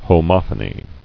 [ho·moph·o·ny]